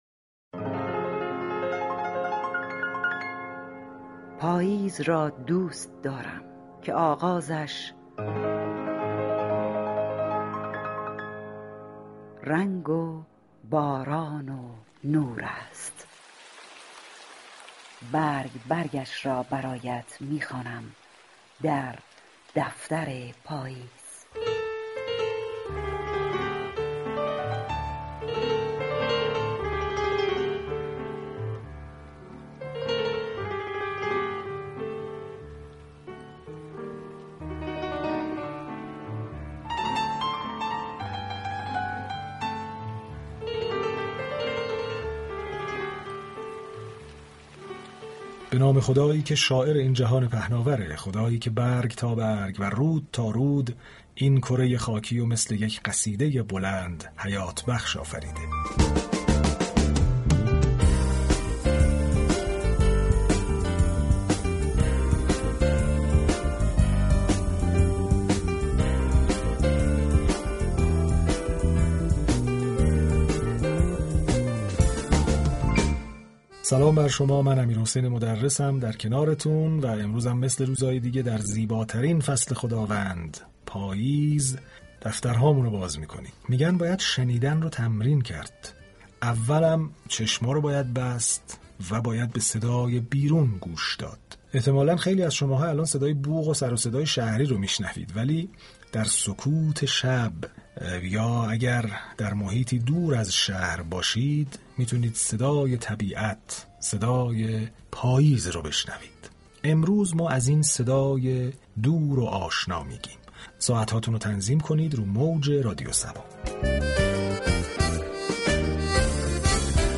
به گزارش روابط عمومی رادیو صبا، یكی از برنامه های این شبكه رادیویی كه تداعی گر فصل پاییز و برای مخاطبان خاطره انگیز است برنامه ادبی «دفتر پاییز» است این برنامه با تورقی بر متون مدرن و كلاسیك به مرور عاشقانه های پاییزی می پردازد و اشعار و متونی عاشقانه را با لحن دلنشین امیرحسین مدرس با موسیقی تلفیق می كند و لحظات به یادماندنی را برای مخاطبان می سازد.